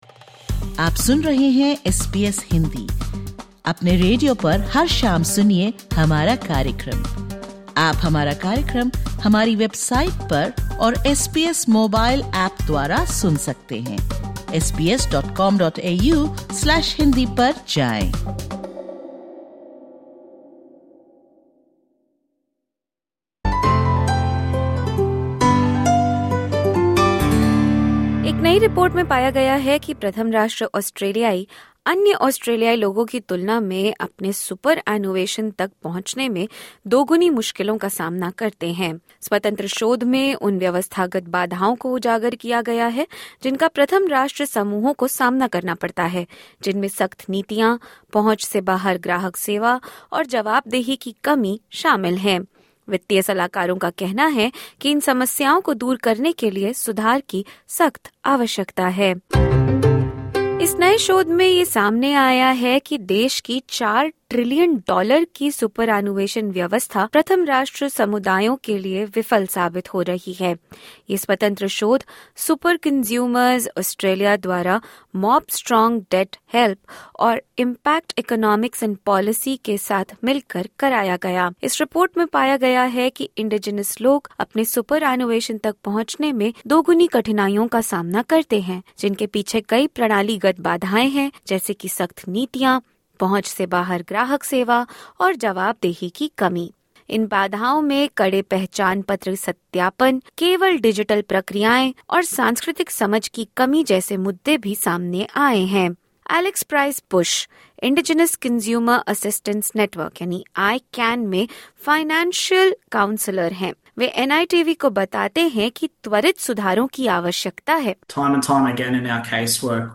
In this episode, we speak to financial counsellors and legal experts about the challenges and the way forward.